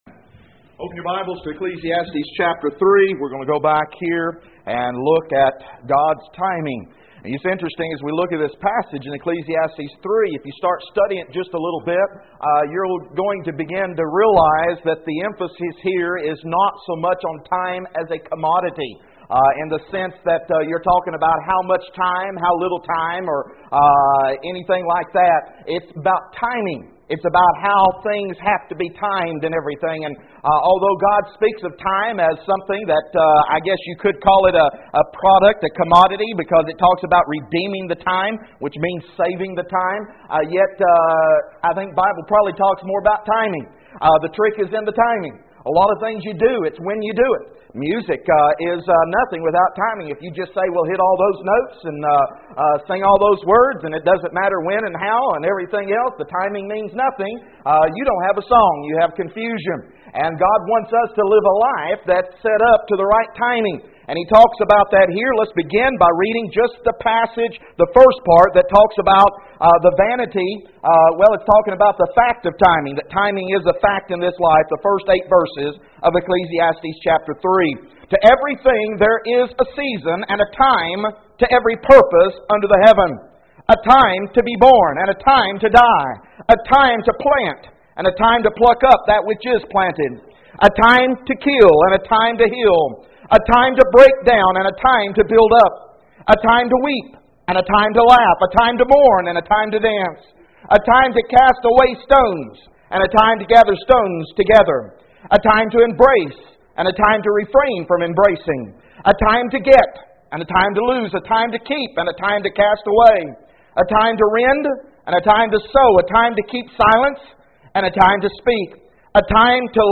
Text: Ecclesiastes 3:1-8 No Outline at this point. For More Information: More Audio Sermons More Sermon Outlines Join the Learn the Bible mailing list Email: Send Page To a Friend